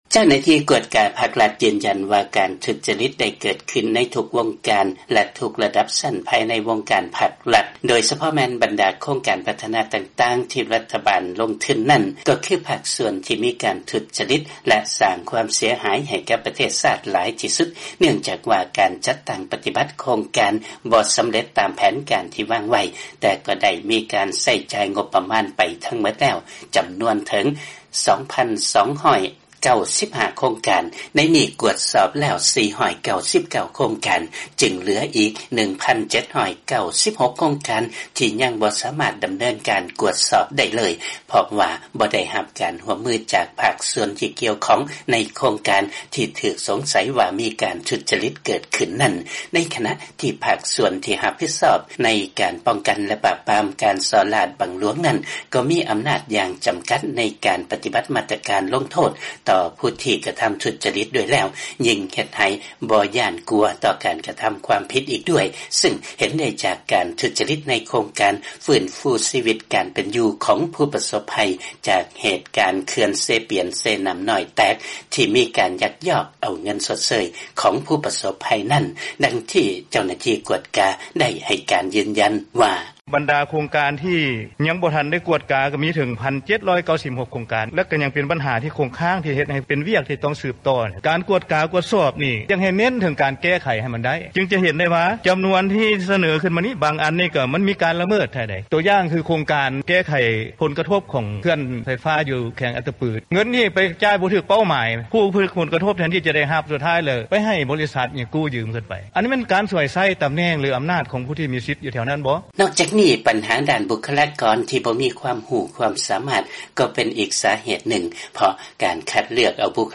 ມີລາຍງານຈາກບາງກອກ.